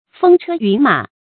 風車云馬 注音： ㄈㄥ ㄔㄜ ㄧㄨㄣˊ ㄇㄚˇ 讀音讀法： 意思解釋： 見「風車雨馬」。